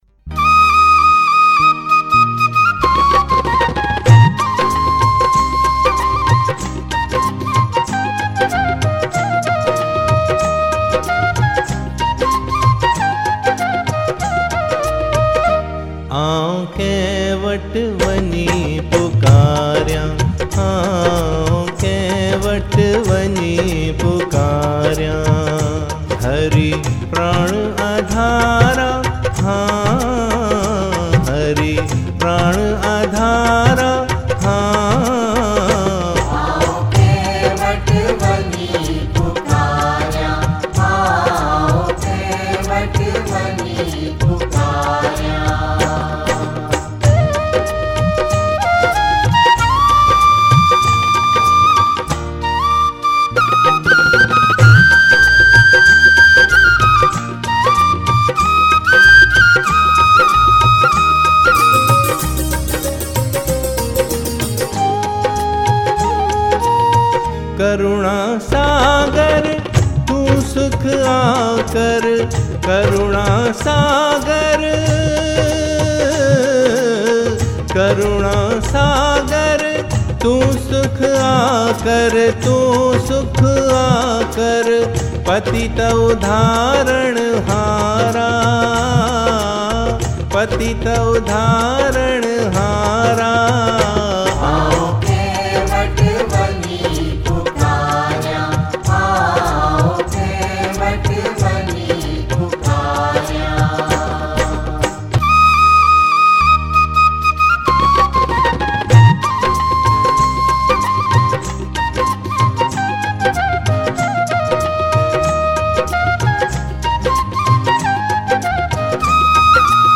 Recorded at: Line-In Studio